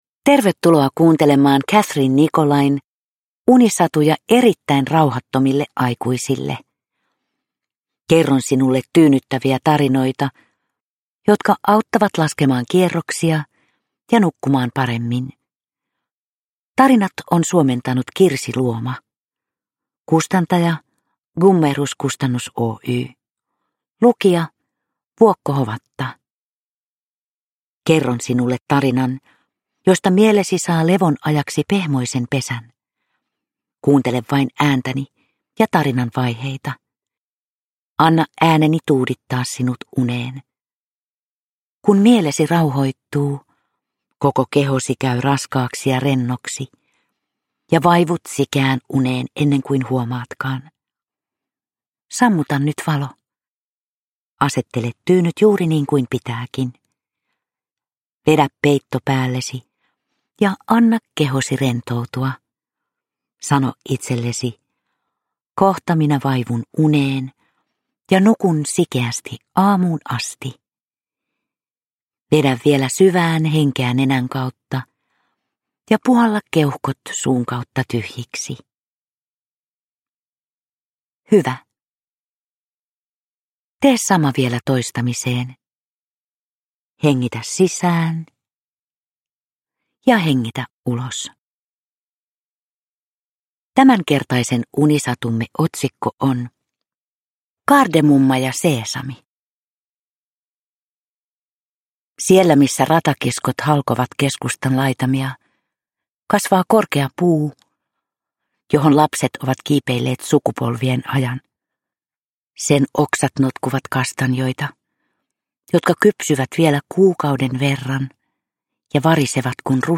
Unisatuja erittäin rauhattomille aikuisille 1 - Kardemumma ja Seesami – Ljudbok
Vuokko Hovatan tyyni ääni saattelee kuulijan lempeästi unten maille.
Uppläsare: Vuokko Hovatta